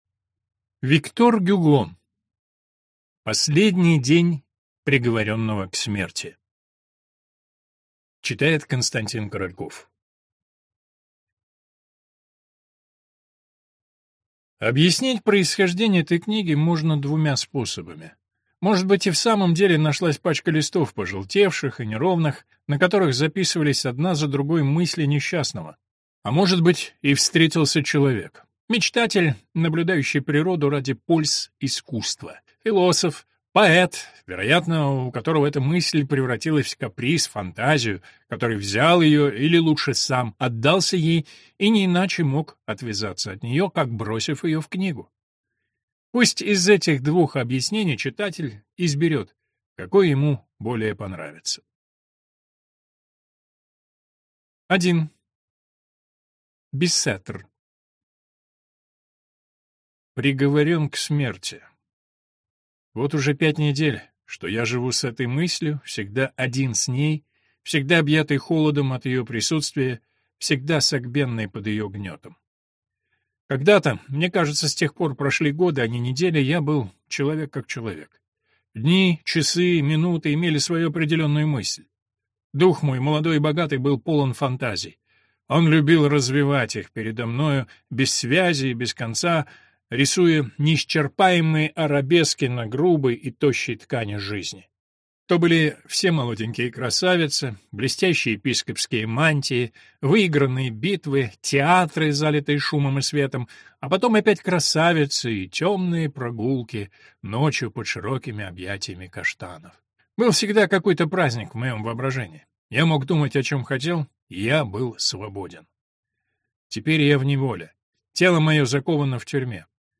Аудиокнига Последний день приговоренного к смерти | Библиотека аудиокниг